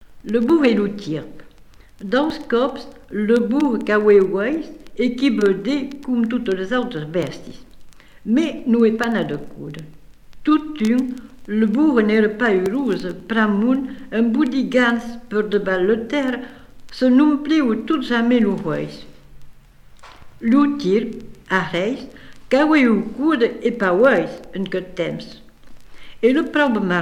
Genre légende
Catégorie Récit